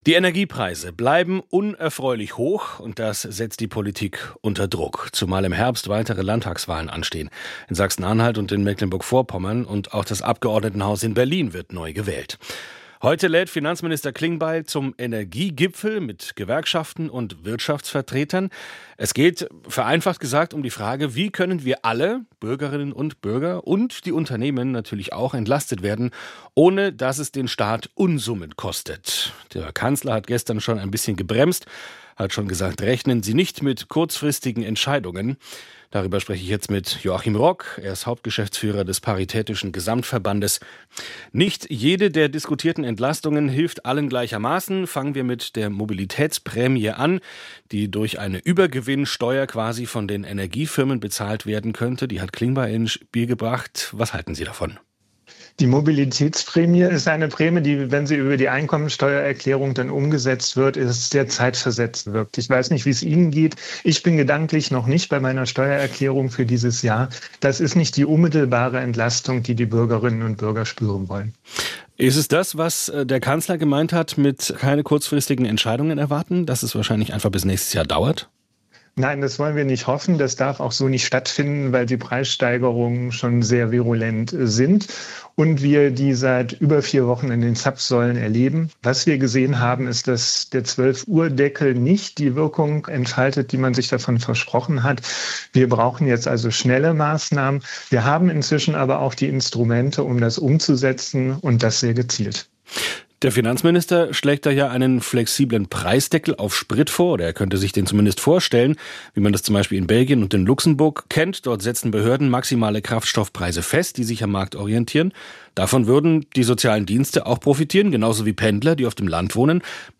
Interviews aus SWR Aktuell als Podcast: Im Gespräch